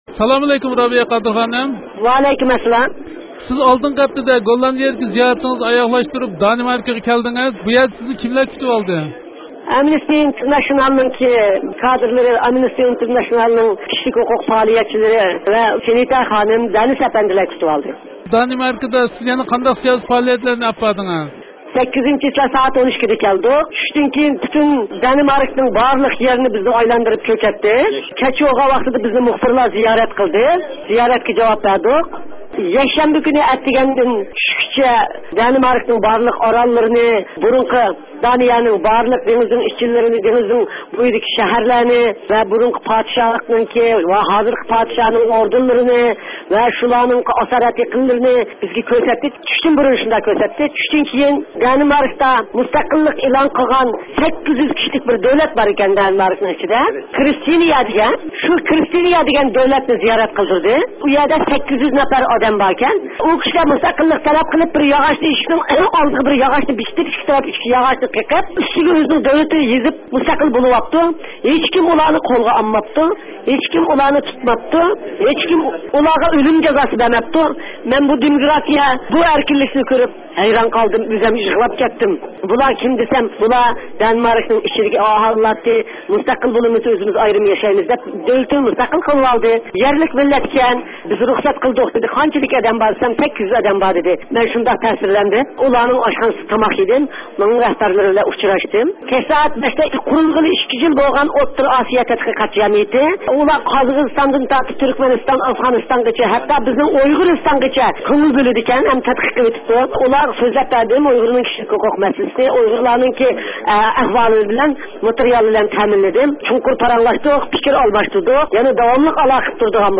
رابىيە خانىم بىلەن ئۆتكۈزگەن سۆھبىتىدىن ئاڭلاڭ.